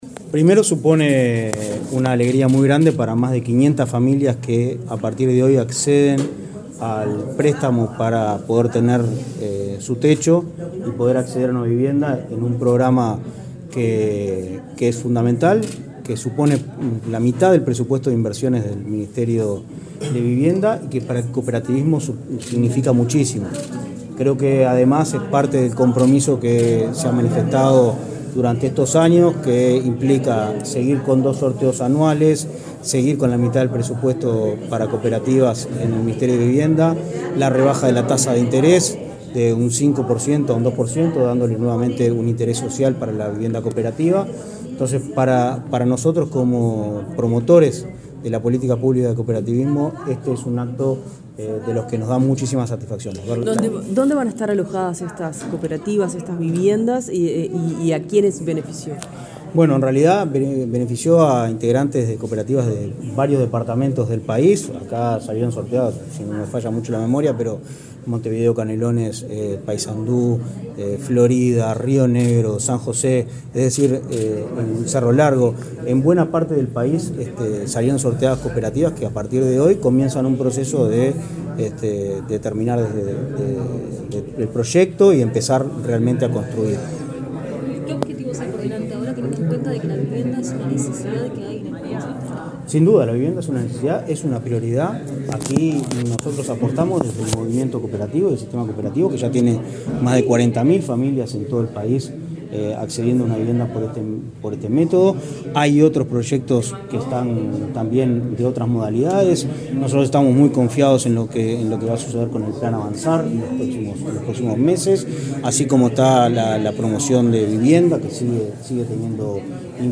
Declaraciones del presidente de Inacoop, Martín Fernández
El presidente del Instituto Nacional del Cooperativismo (Inacoop), Martín Fernández, dialogó con la prensa, luego del sorteo de cupos para la